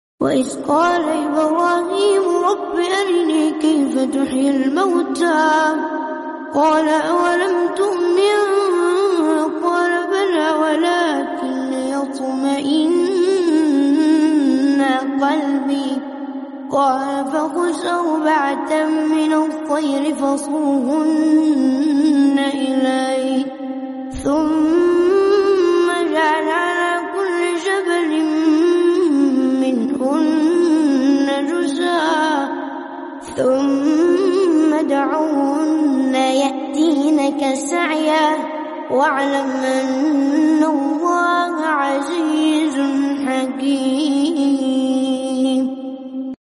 A peaceful recitation of alquran